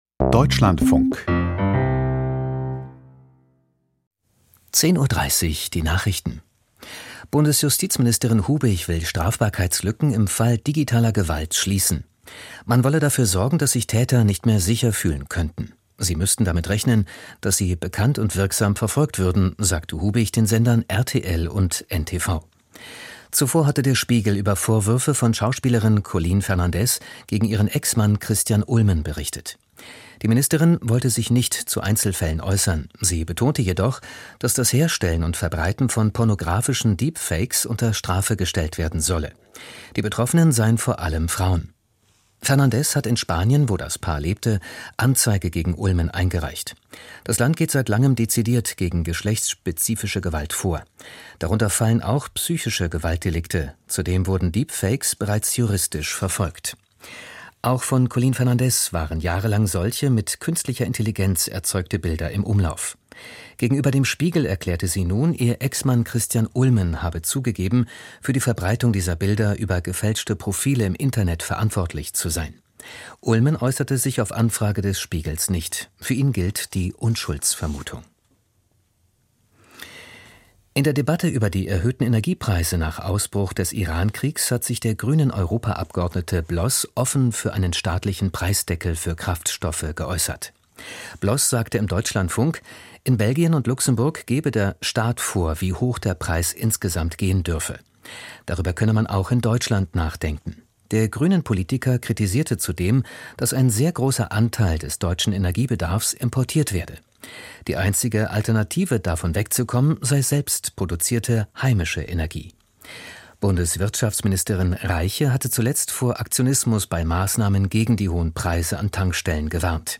Die Nachrichten vom 20.03.2026, 10:30 Uhr
Aus der Deutschlandfunk-Nachrichtenredaktion.